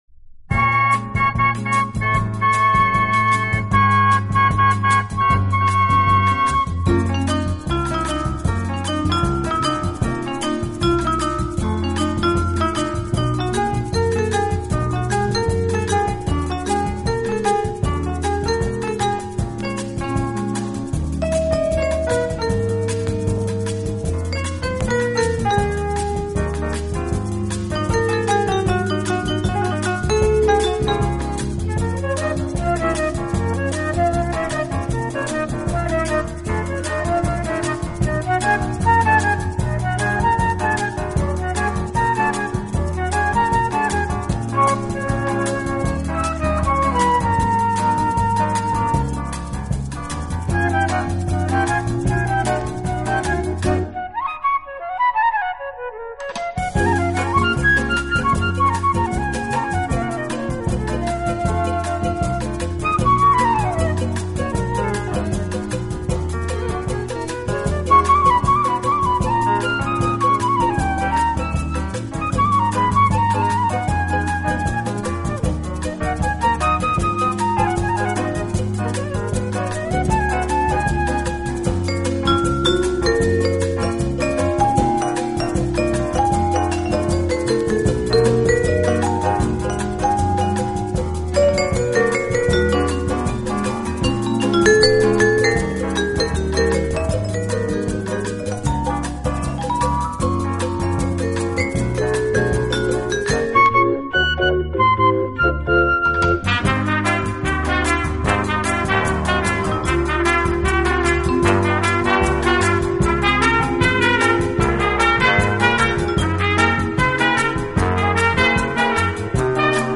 【轻音乐】
演奏轻柔优美，特別是打击乐器的演奏，具有拉美音乐独特的韵味。